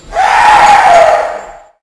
scream_12.wav